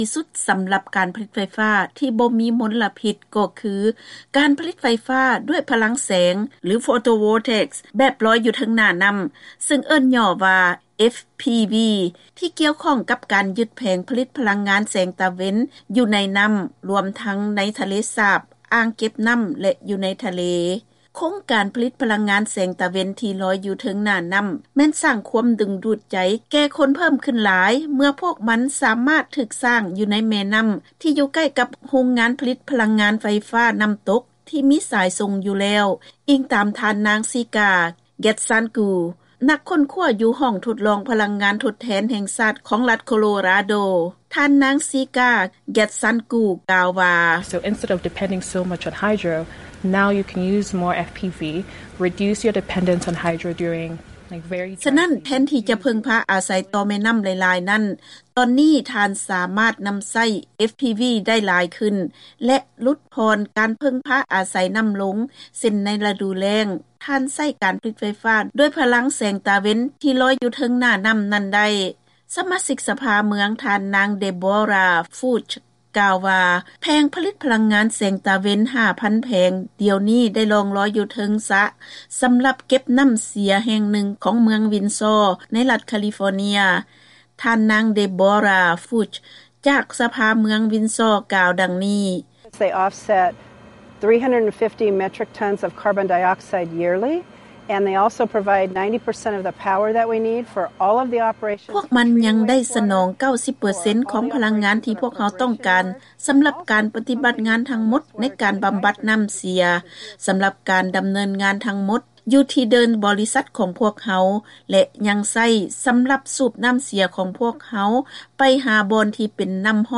ເຊີນຟັງລາຍງານກ່ຽວກັບຄວາມນິຍົມຜະລິດພະລັງງານໄຟຟ້າດ້ວຍແຜງພະລັງງານແສງຕາເວັນໃນທະວີບອາເມຣິກາເໜືອ